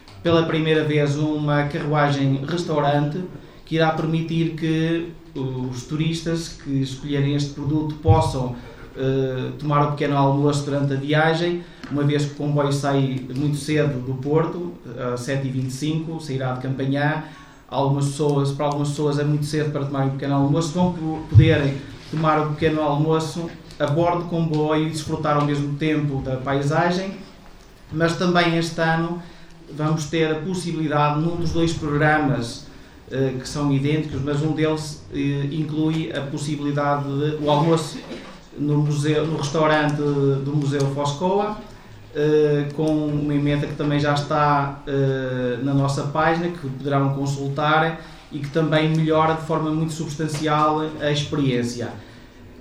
Na conferência de imprensa, realizada no salão nobre da Câmara Municipal de Vila Nova de Foz Côa, no passado dia 7 de fevereiro, para a apresentação do programa das Amendoeiras em Flor, também foi promovida a Rota das Amendoeiras da CP e foi assinado um protocolo entre esta empresa de comboios e a autarquia, nesta que é a rota mais antiga.